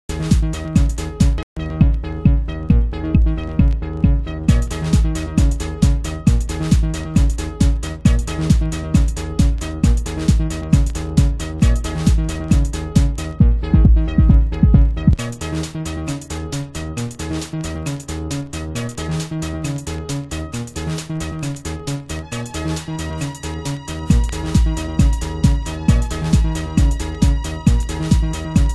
チープなサウンドが醸しだすシカゴGROOVE！
盤質：少しチリノイズ有